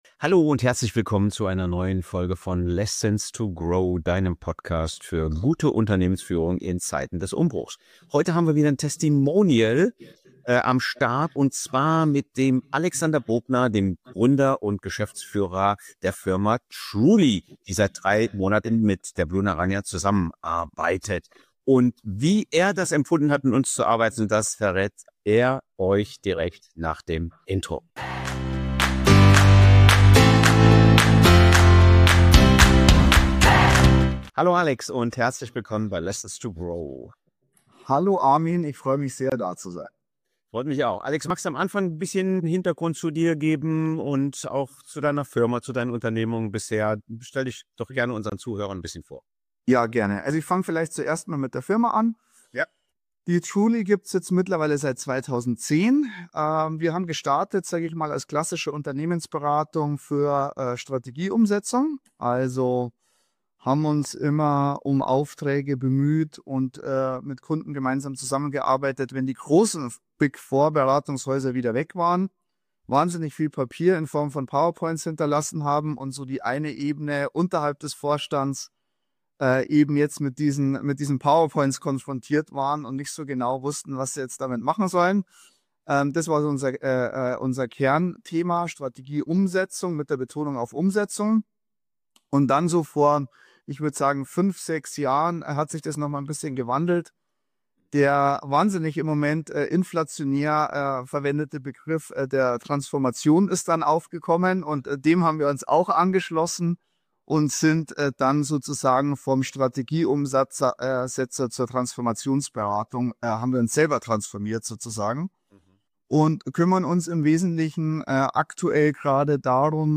Erfolg mit digitaler Akquise - Gespräch